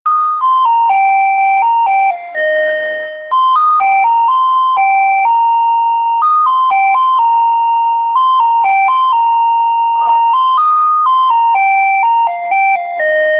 bell-ringing_30407.mp3